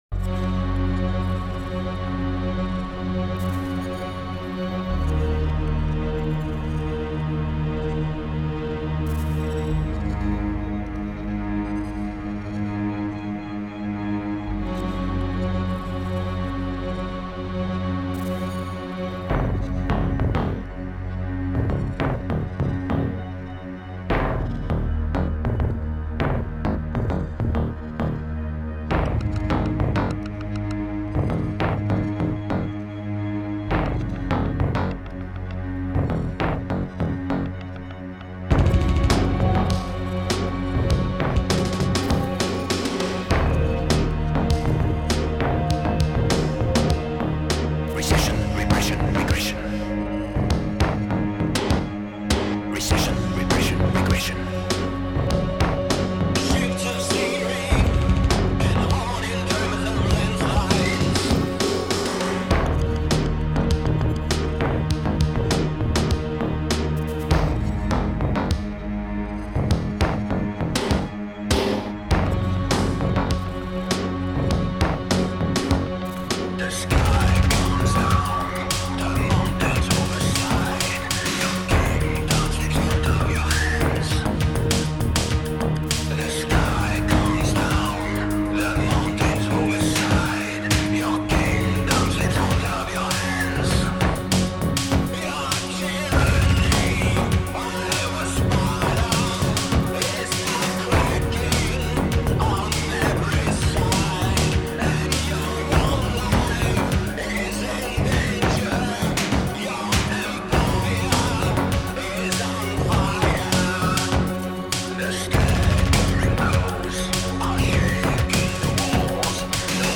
Альтернативная музыка